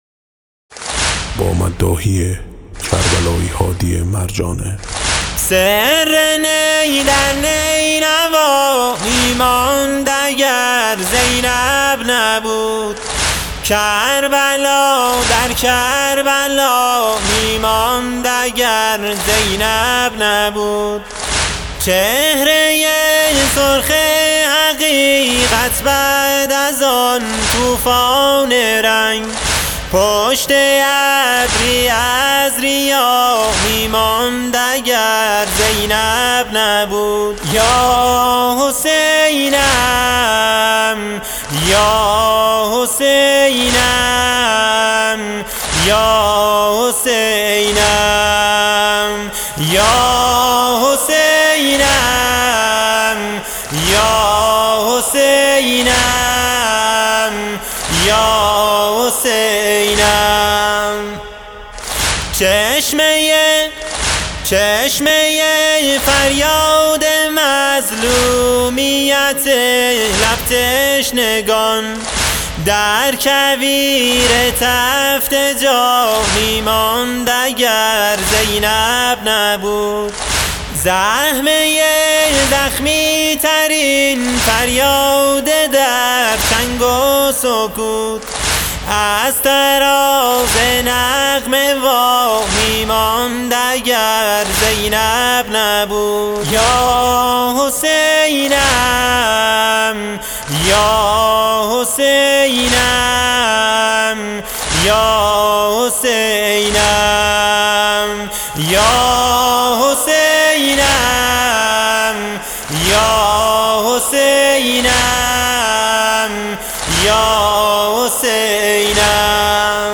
جزئیات و متن نوحه
این نوحه جانسوز، به روایت حوادث و حال و هوای شب عاشورا، شبی که امام حسین (ع) و یاران باوفایشان آماده وداع با دنیا و استقبال از شهادت شدند، می‌پردازد. با نوای دلنشین و تأثیرگذار، تلاش شده تا عمق مصیبت و ایثار این شب عظیم به تصویر کشیده شود.
در این نوحه، به مقام شامخ امام حسین (ع) و فداکاری‌های ایشان و یارانشان اشاره شده و شنونده را به فضایی معنوی و پر از حزن و اندوه دعوت می‌کند.